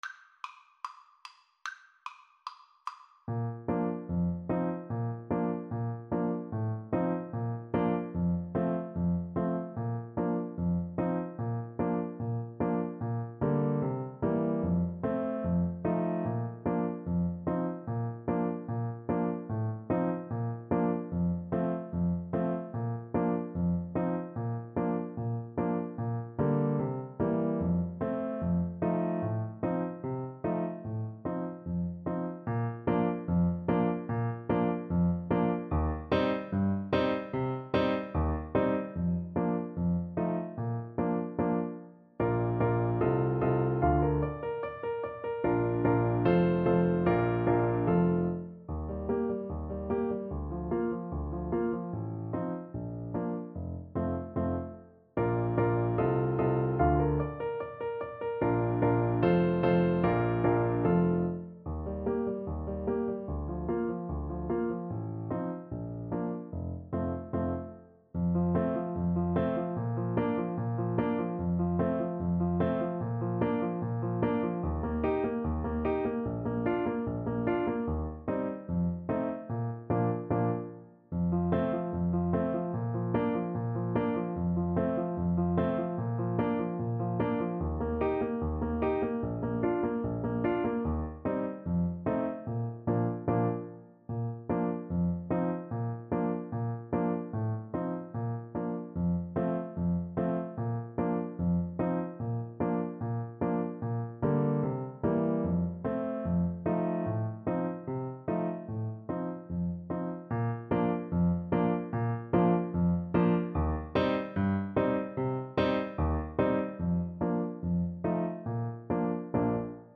Allegretto = 74